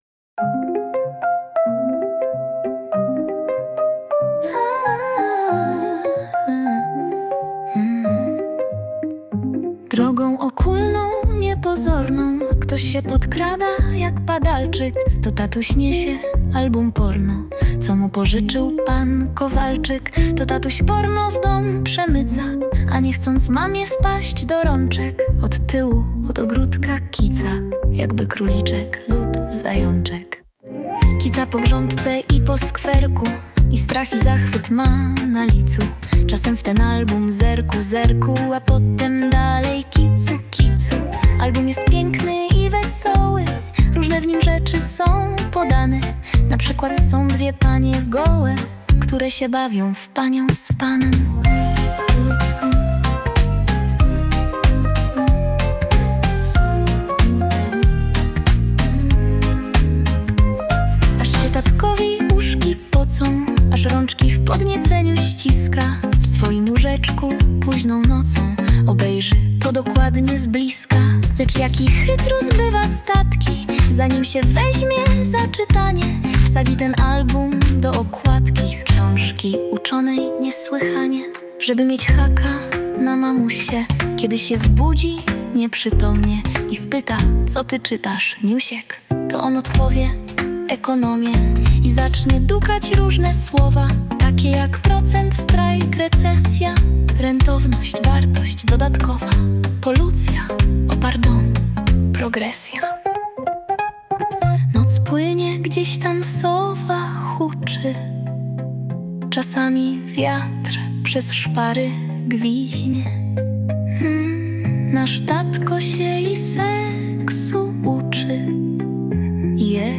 (cover) Klasyka w nowej odsłonie.